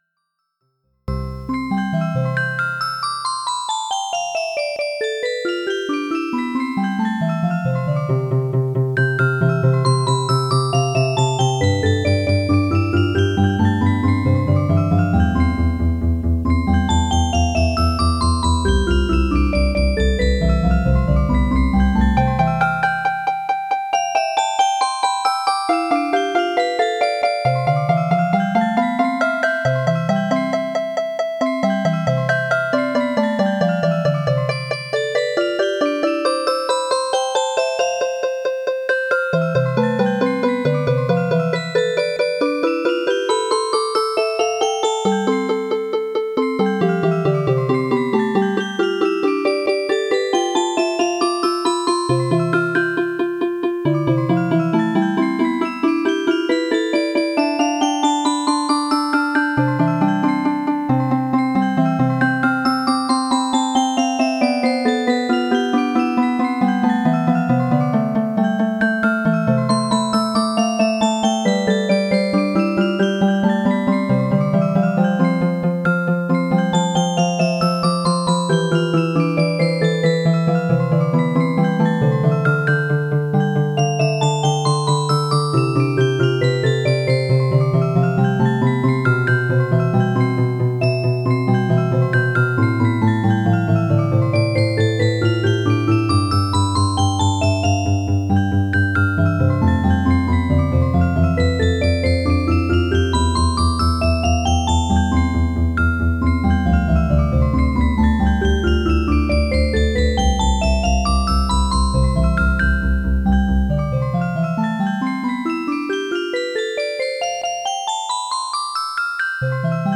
It uses the Windows midi synthesizer and has two user-selectable voices. Each voice can be any of the 128 standard midi instruments.
The first three are the same tune with different voices.